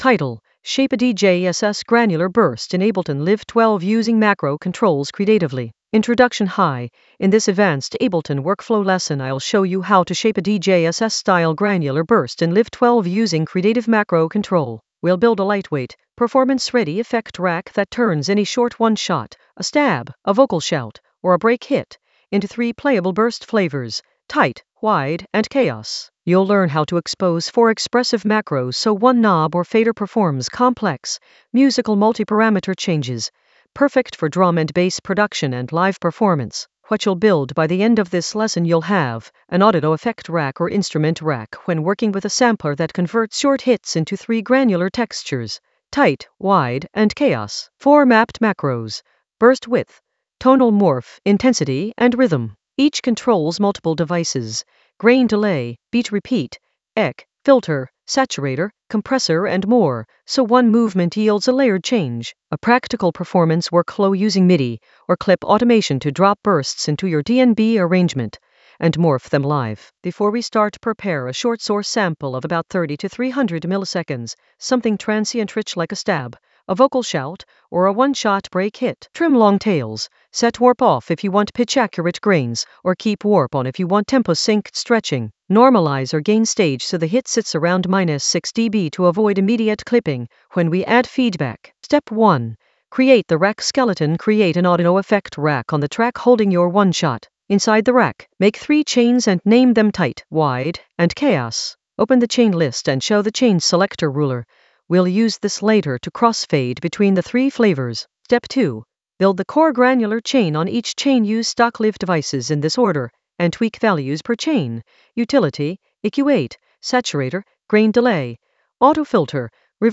An AI-generated advanced Ableton lesson focused on Shape a DJ SS granular burst in Ableton Live 12 using macro controls creatively in the Workflow area of drum and bass production.
Narrated lesson audio
The voice track includes the tutorial plus extra teacher commentary.